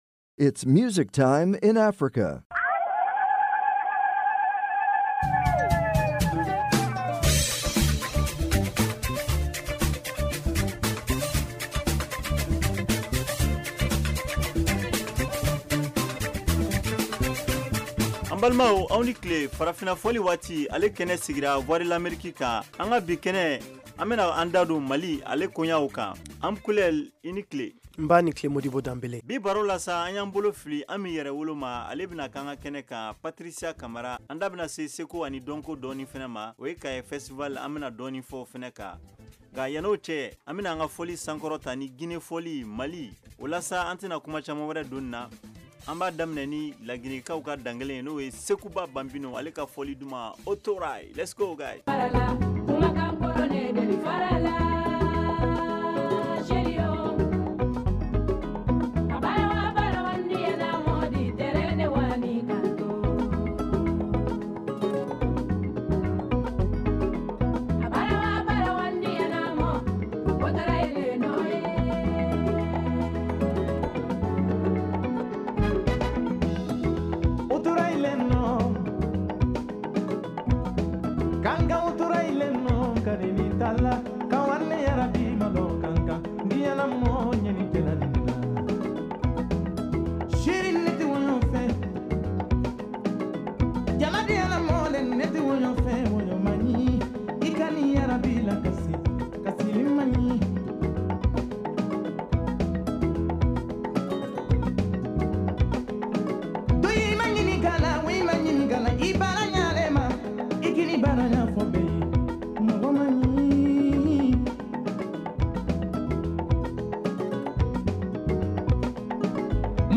Farafina Fɔli Waati est une émission culturelle et musicale interactive en Bambara de la VOA.